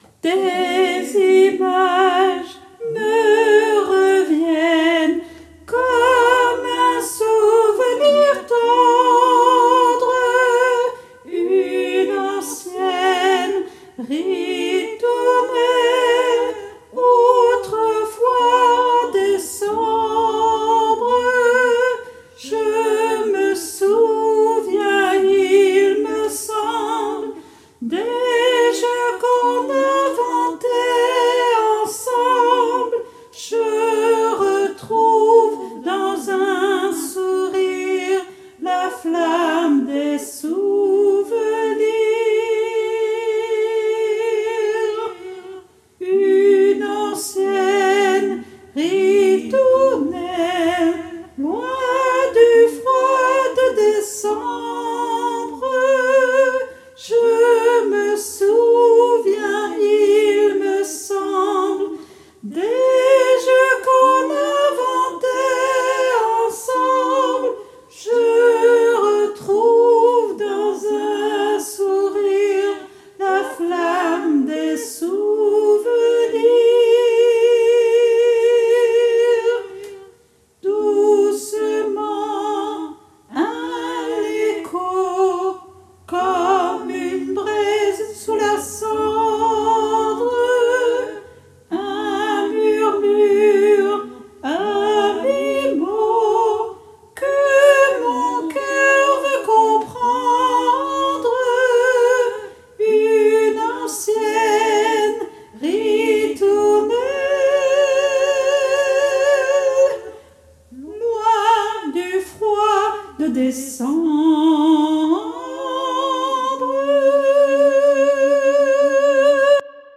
MP3 versions chantées
Soprano et autres voix en arrière-plan